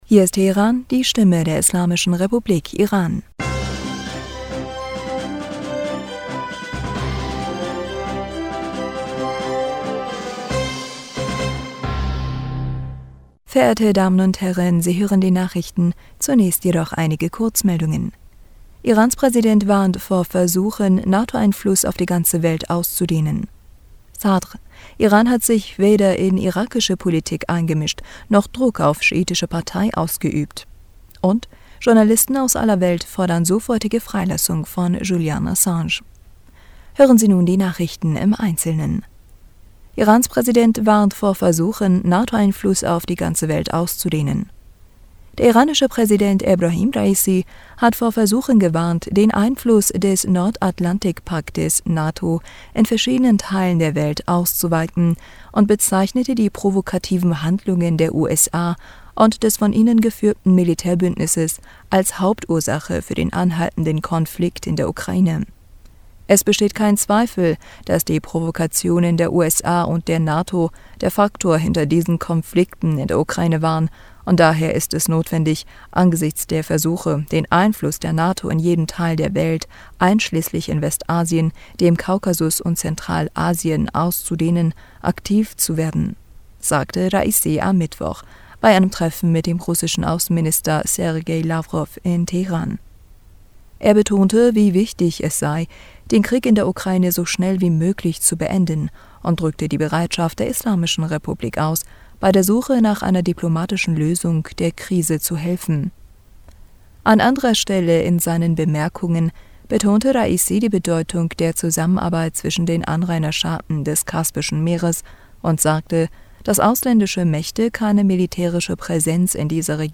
Nachrichten vom 23. Juni 2022
Die Nachrichten von Donnerstag dem 23. Juni 2022